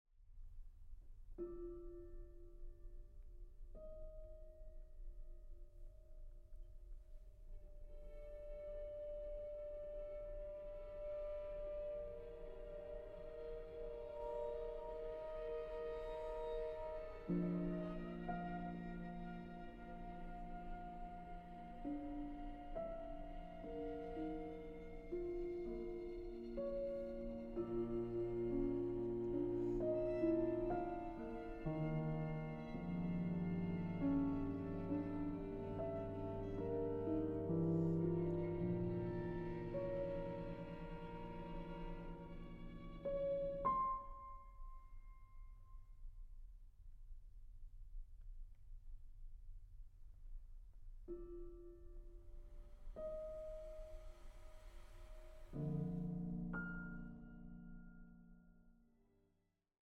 Aufnahme: Festeburgkirche Frankfurt, 2024